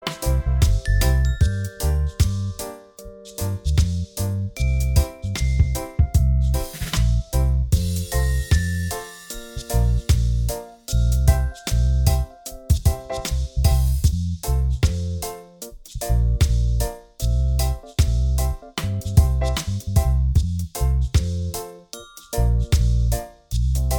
Minus All Guitars Reggae 3:56 Buy £1.50